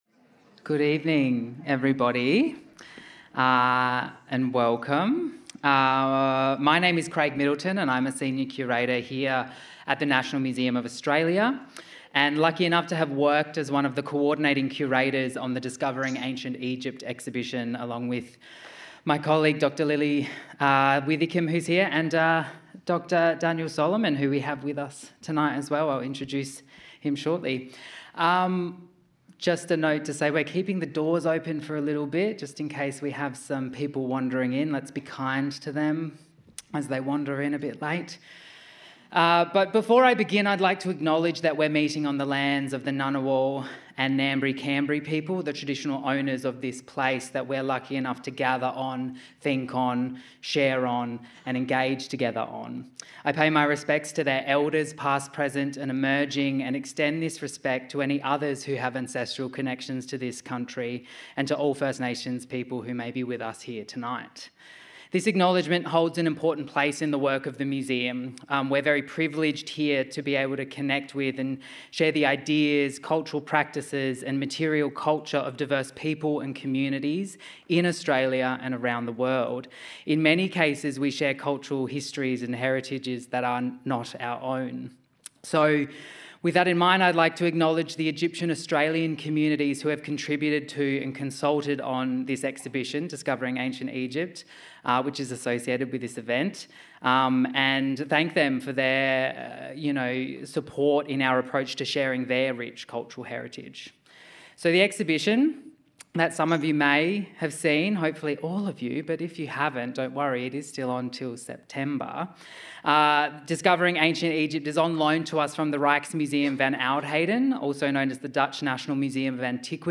in conversation
spotlight-conversation-ethics.mp3